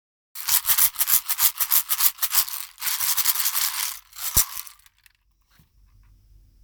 竹ボックスシェ-カー
自然素材が織り成す「サラサラシャカシャカ〜」優しい音色です。
素材： 竹 小石 種